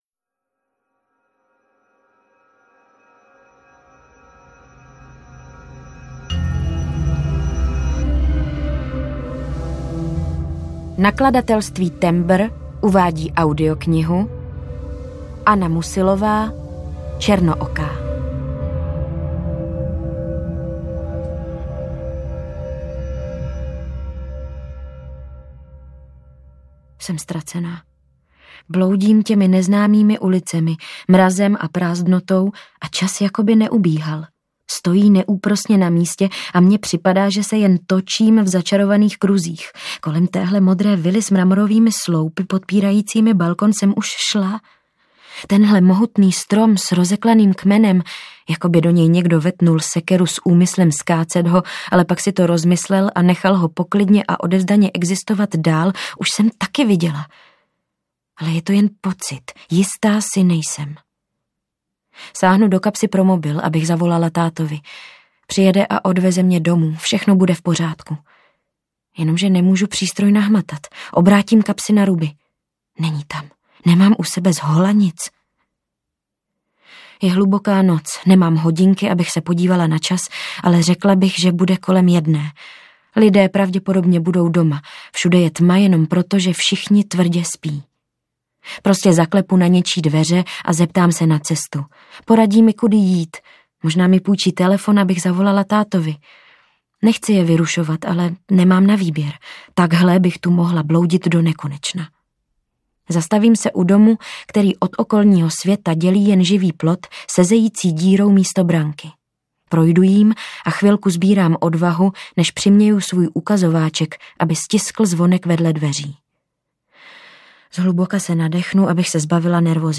Černooká audiokniha
Ukázka z knihy